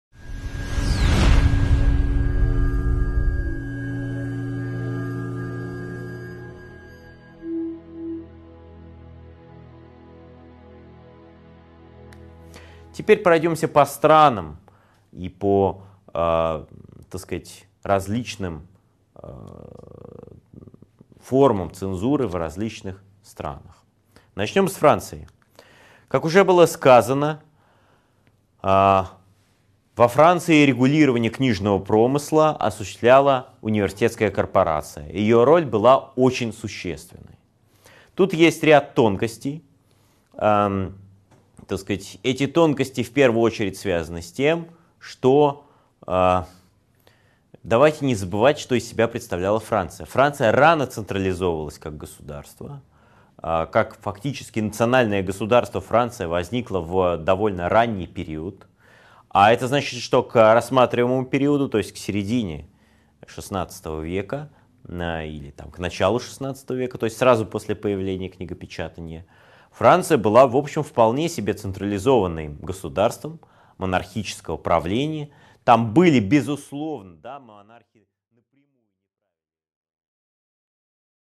Аудиокнига 3.4 Цензура во Франции | Библиотека аудиокниг
Прослушать и бесплатно скачать фрагмент аудиокниги